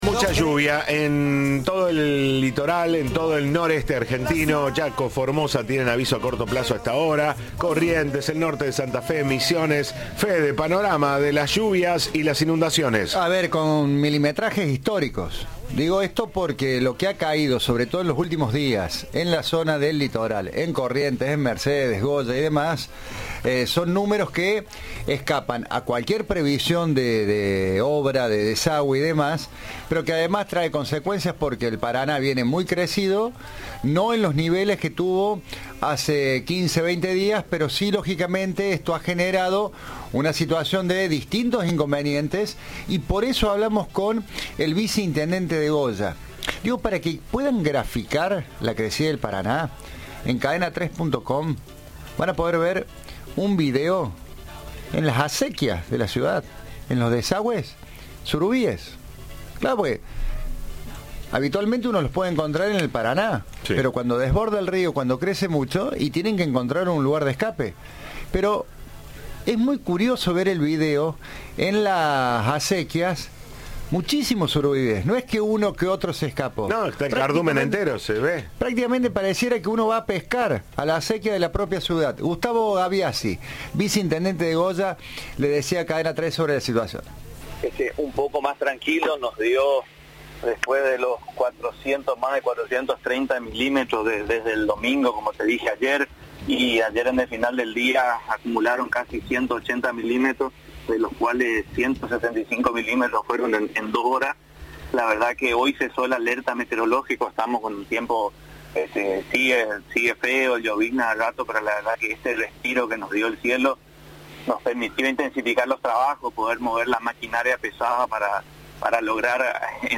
El vicenintendente de Goya, Gustavo Gabiassi, explicó en diálogo con Cadena 3 que Goya cuenta con uno de los grandes deltas del Paraná, y cuando las aguas crecen, los peces, en especial el surubí, buscan refugio en corrientes más tranquilas, lo que explicaría su aparición en estos canales secundarios.